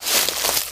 High Quality Footsteps
STEPS Bush, Walk 23.wav